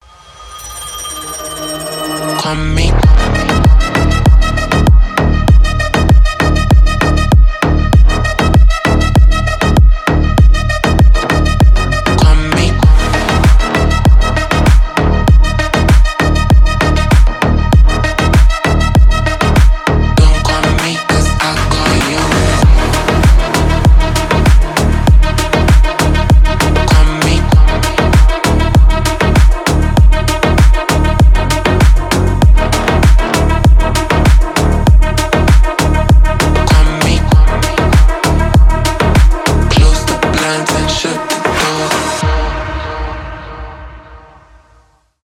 g-house
midtempo
slap house , brazilian bass